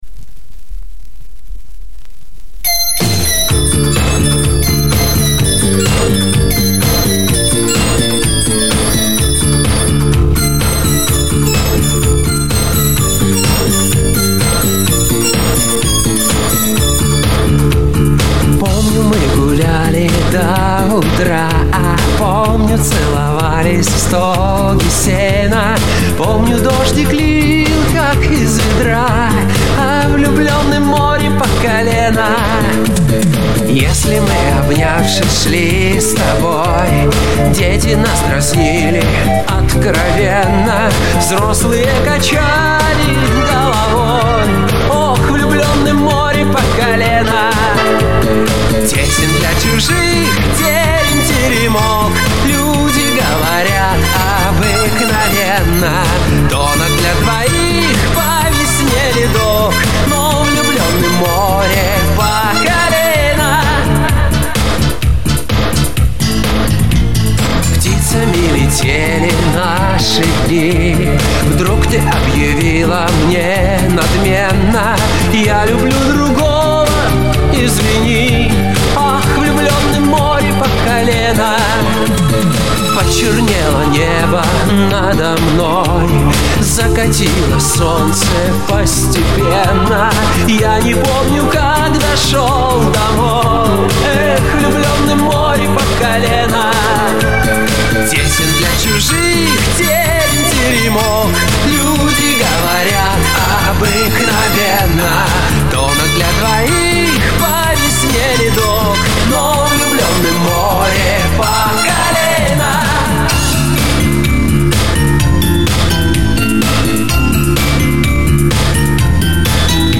ОЦИФРОВКА С ПЛАСТИНКИ